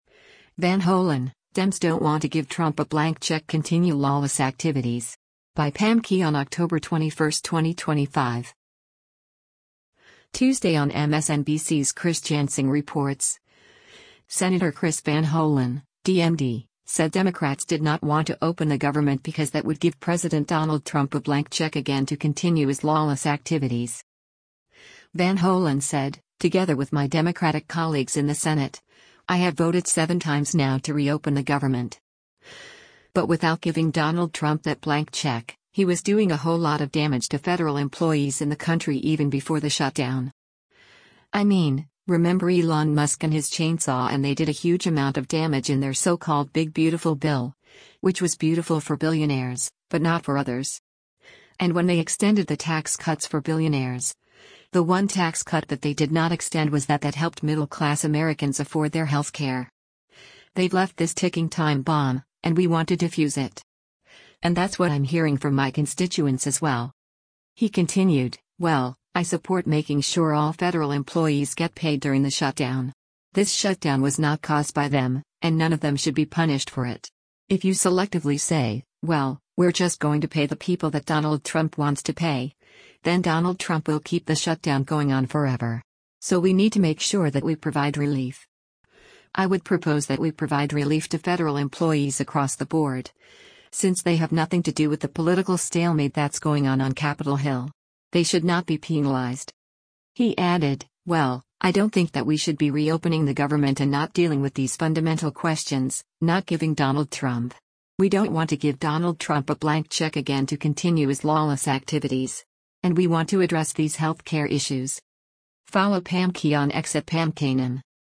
Tuesday on MSNBC’s “Chris Jansing Reports,” Sen. Chris Van Hollen (D-MD) said Democrats did not want to open the government because that would give President Donald Trump a “blank check again to continue his lawless activities.”